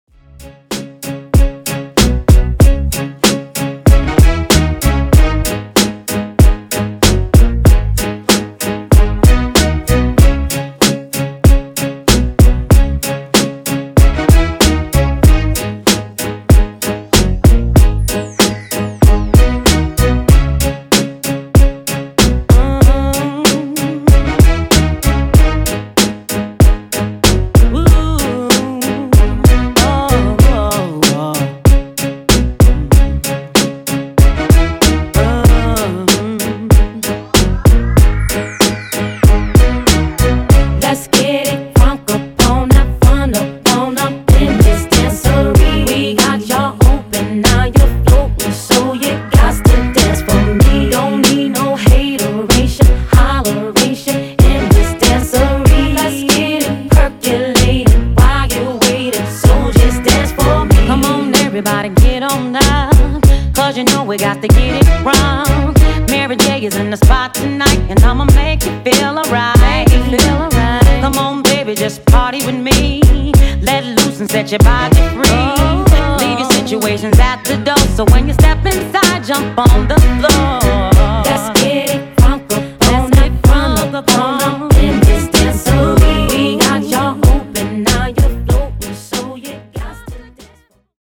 Genres: 80's , TIK TOK HITZ , TOP40
Clean BPM: 110 Time